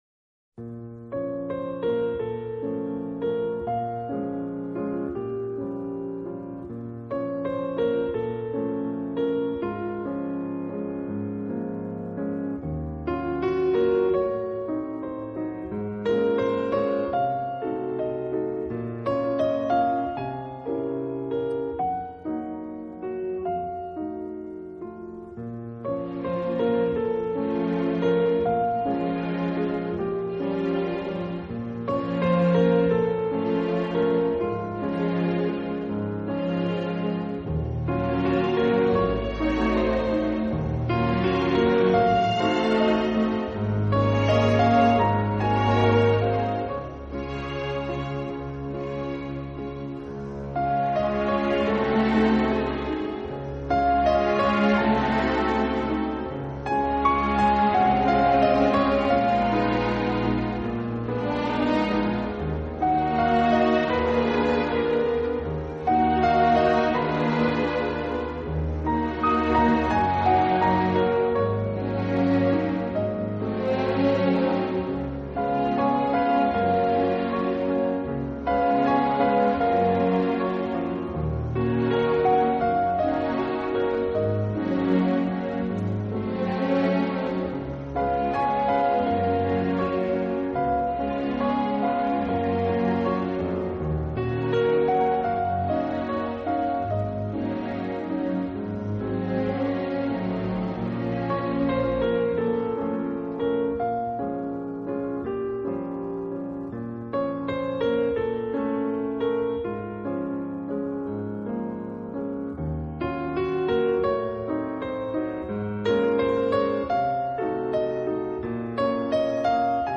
钢琴纯乐